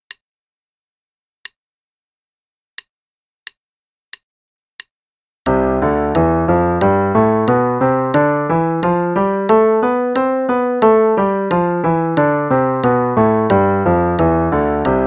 Play-along: C Major Scale (8ths, qn=90)
Play-along_C Major Scale (8ths, qn=90).mp3